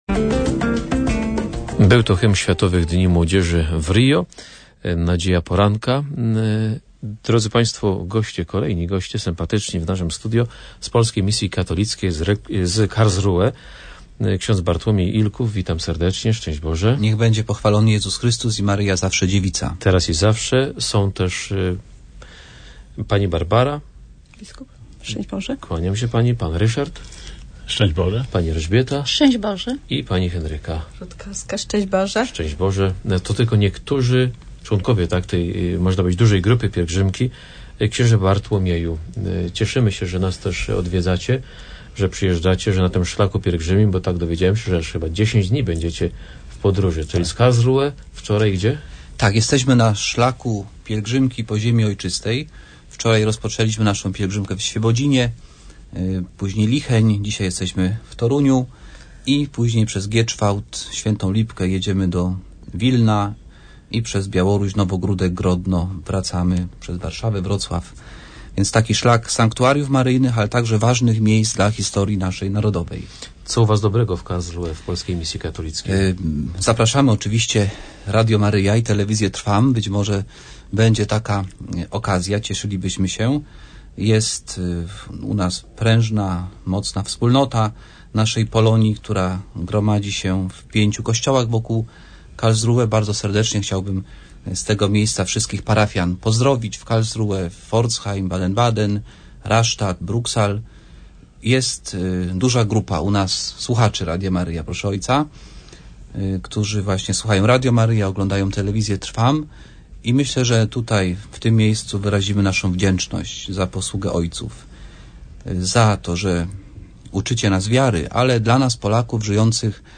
Audycja w Radiu Maryja z udziałem przedstawicieli Polskiej Misji Katolickiej w Karlsruhe: